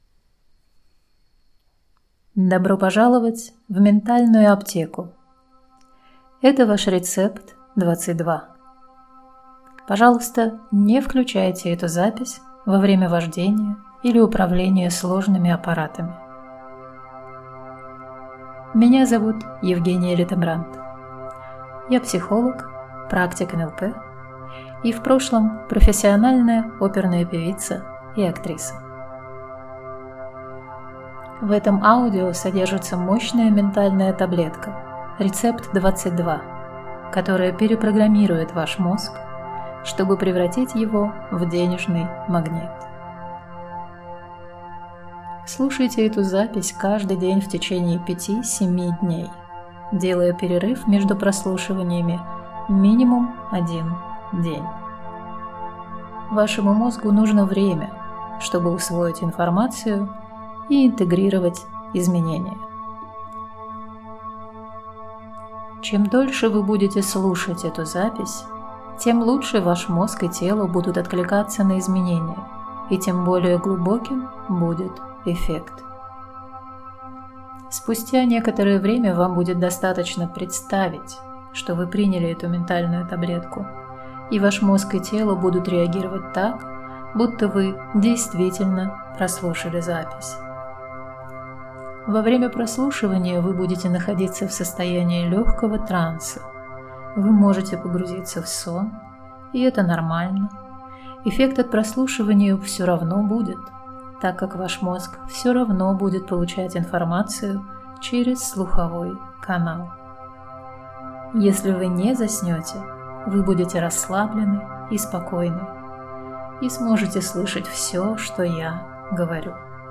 Рецепт-22-Бинаурал.mp3